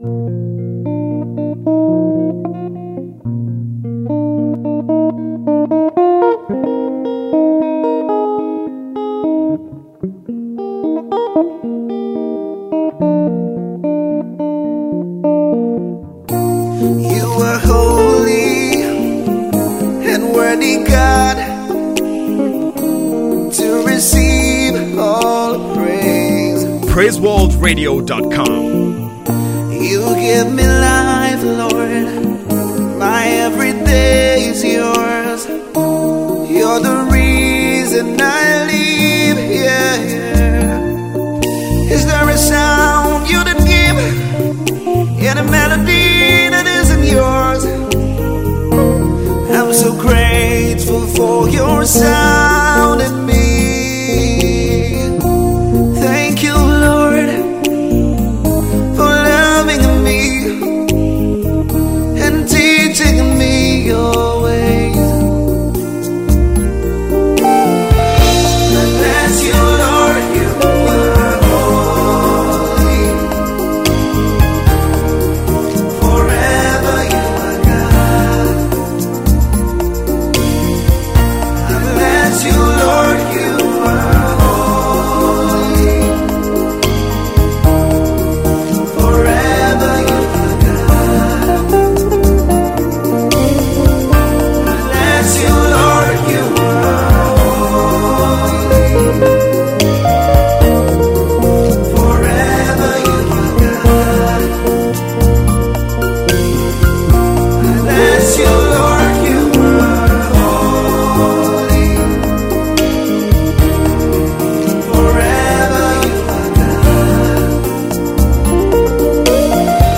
worship tune